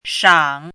“垧”读音
shǎng
垧字注音：ㄕㄤˇ
国际音标：ʂɑŋ˨˩˦